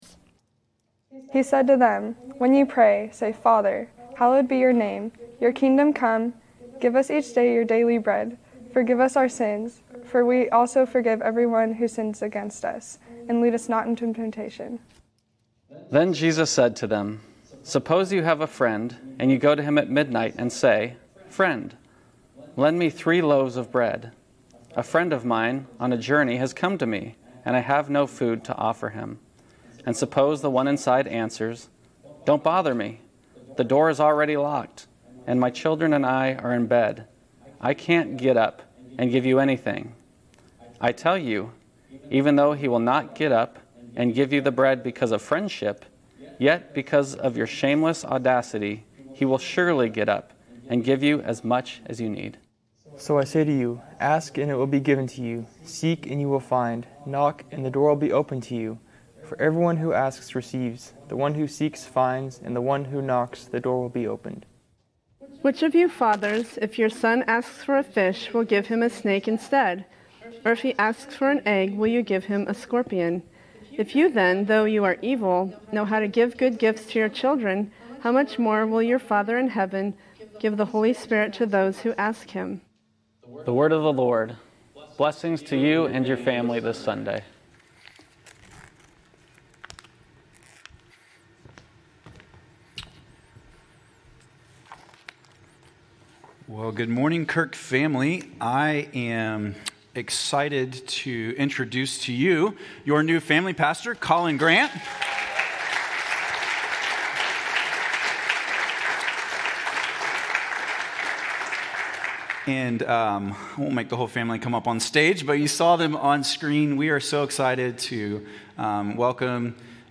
A message from the series "House of Prayer."